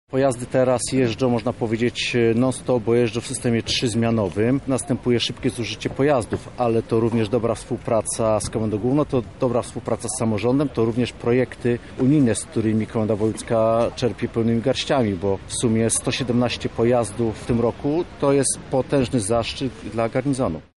– Potrzebowaliśmy nowych samochodów, choć stare są ciągle w użyciu – tłumacz komendant Dariusz Działo